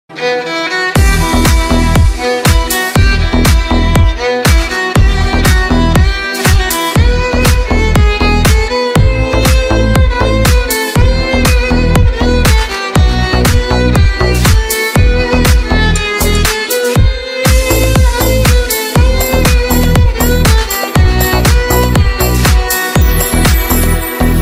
Its catchy, powerful chorus
modern, upbeat rendition